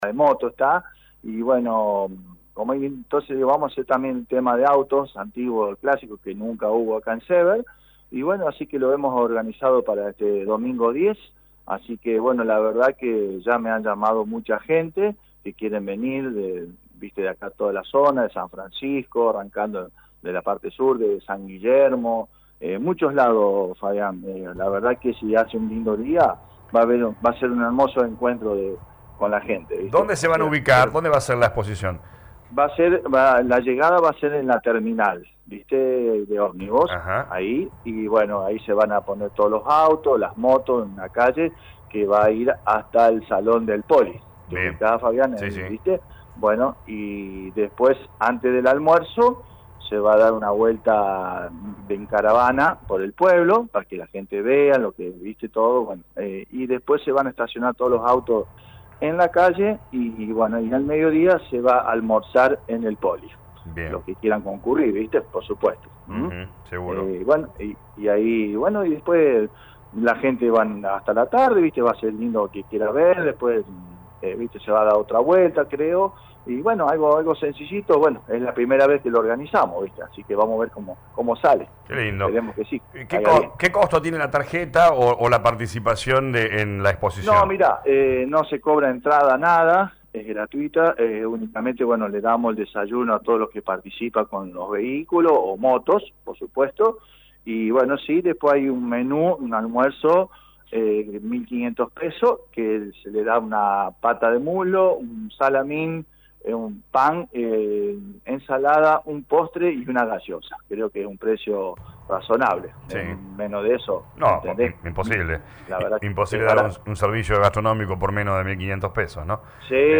habló con LA RADIO 102.9 FM